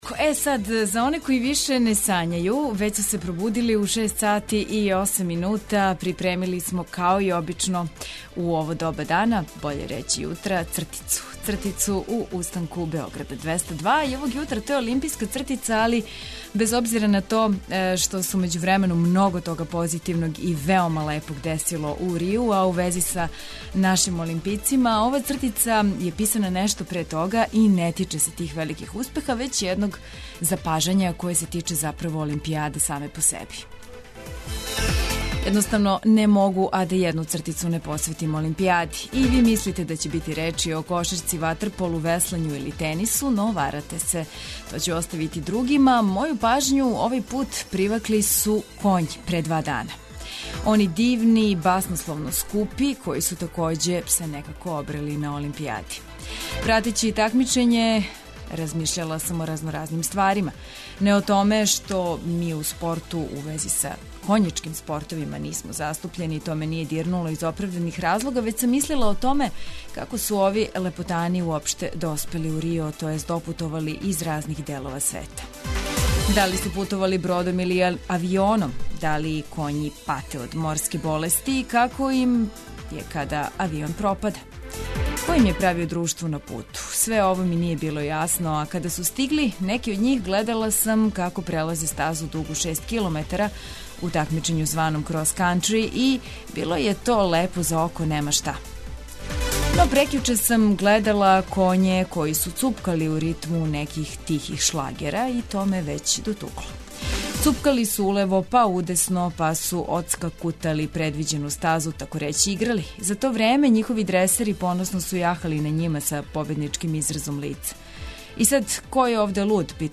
Поред прегледа штампе, сервисних информација, прогнозе и стања на путевима, скрећемо пажњу и на питања слушалаца репортера. Вршчани се жале на то што је стара пијаца затворена.